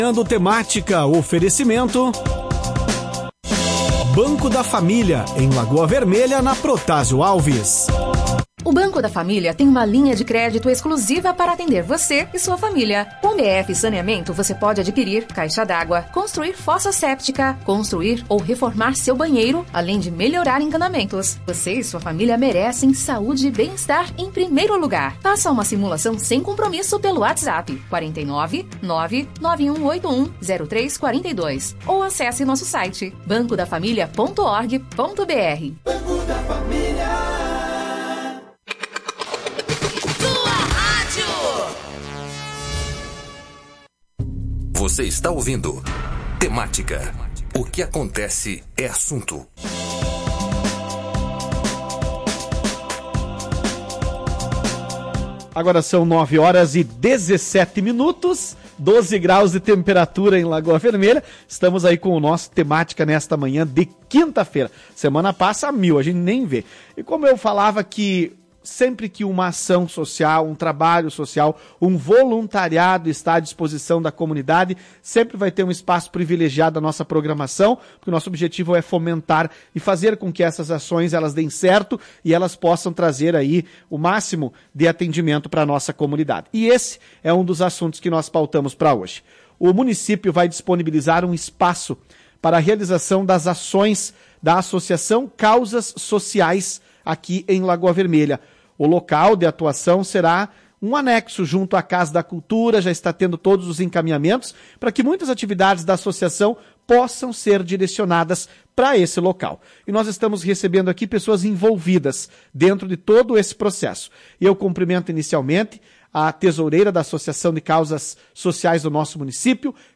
Saiba mais sobre a atuação da Associação Causas Sociais, ouvindo a entrevista com o secretário de Ação Social e Habitação